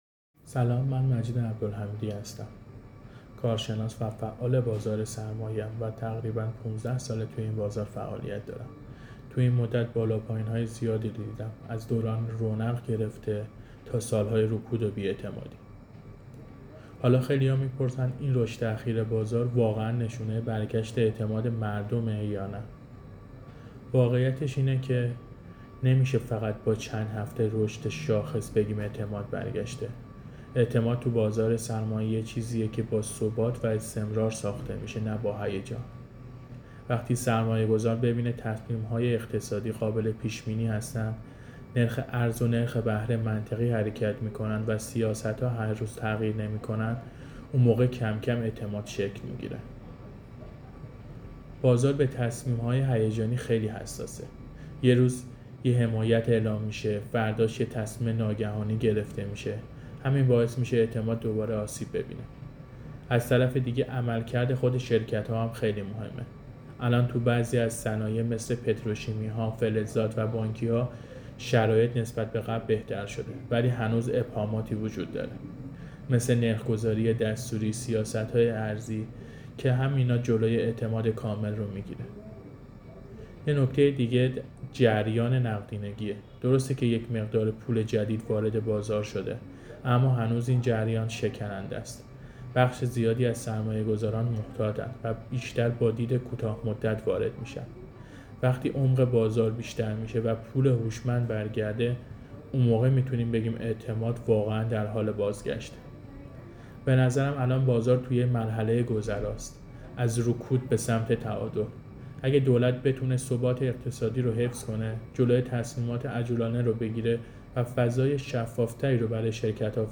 در گفت‌وگو با بورس نیوز